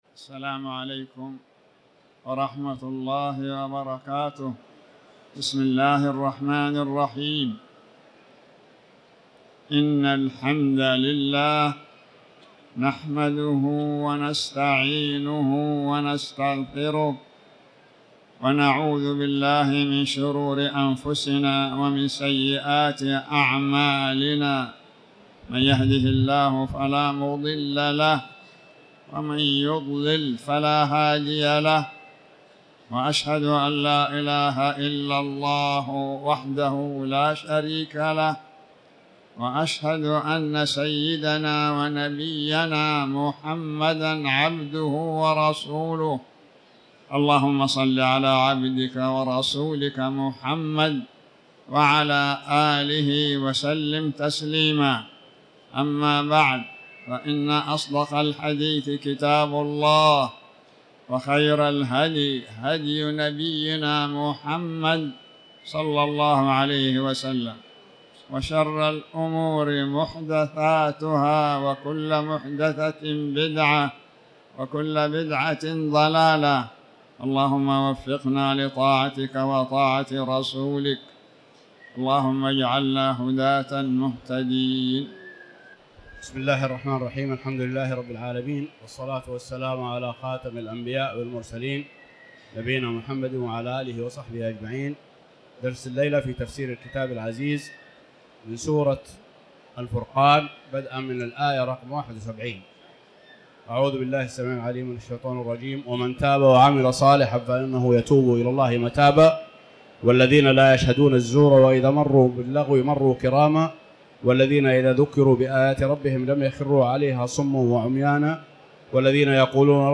تاريخ النشر ٢٨ شوال ١٤٤٠ هـ المكان: المسجد الحرام الشيخ